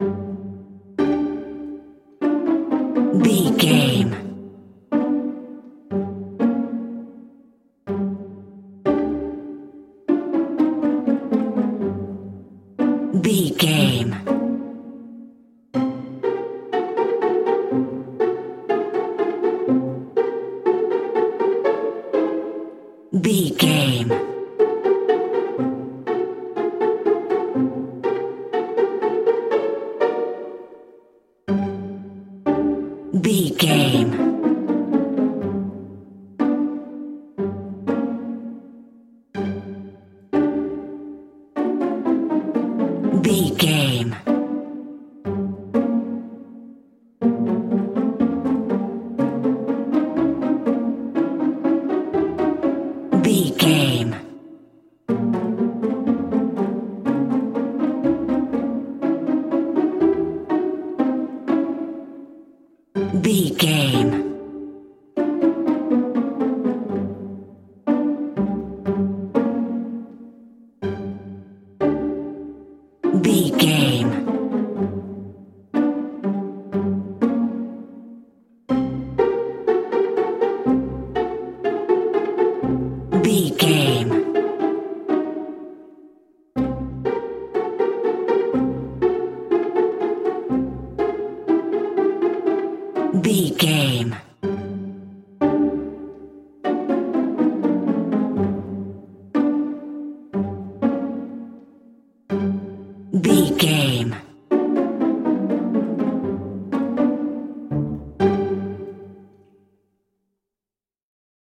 Lydian
pizzicato
glockenspiel
marimba
playful
spooky
medium tempo
double bass
cello
violin